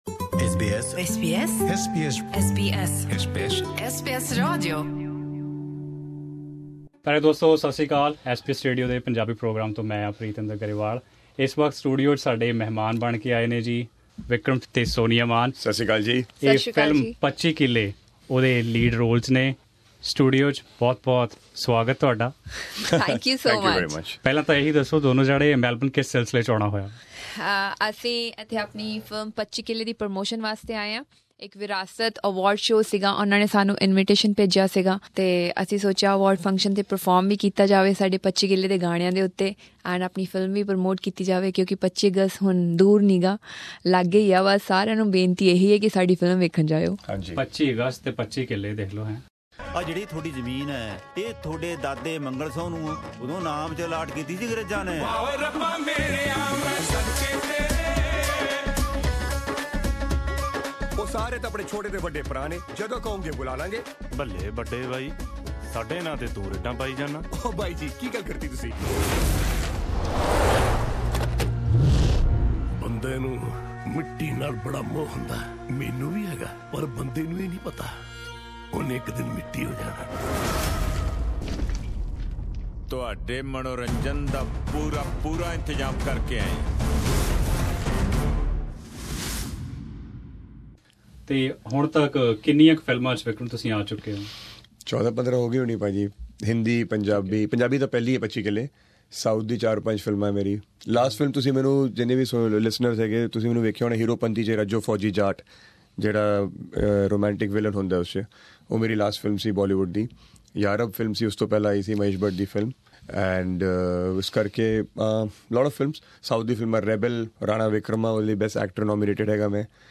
at SBS Melbourne Studio